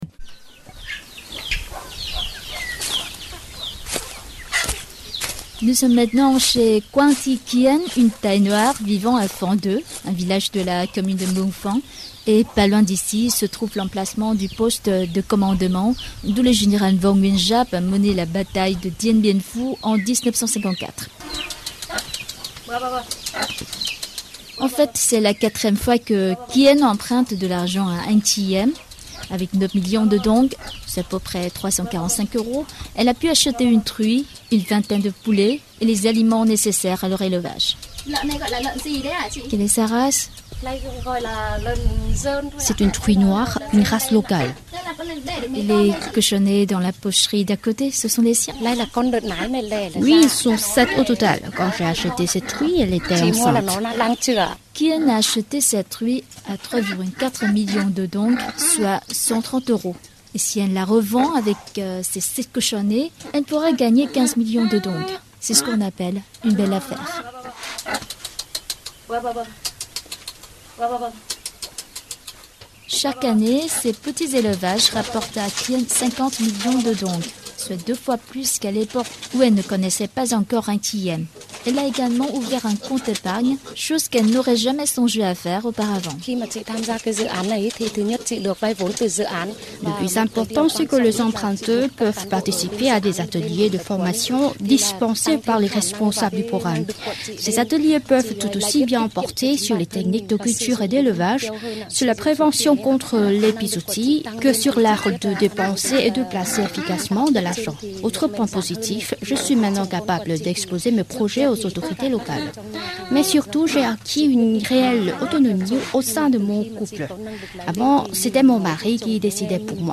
Merci à La Voix du Vietnam pour ce reportage !